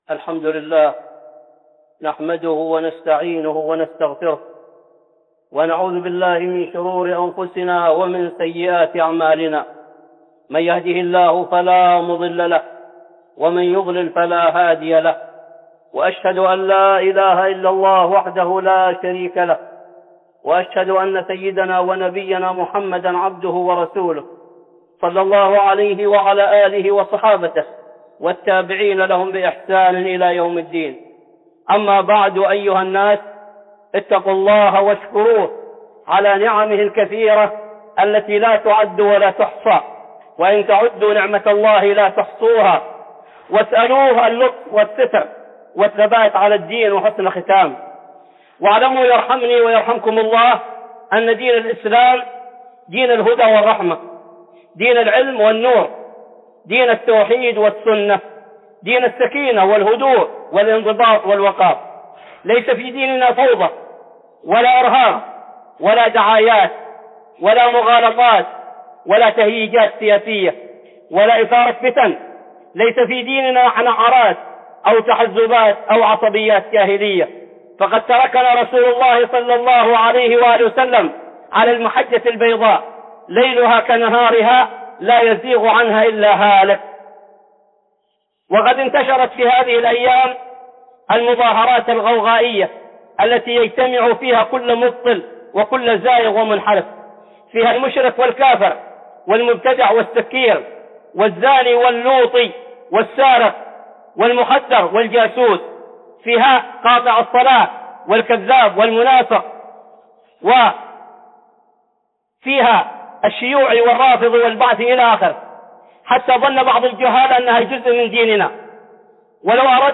(خطبة جمعة) مفاسد المظاهرات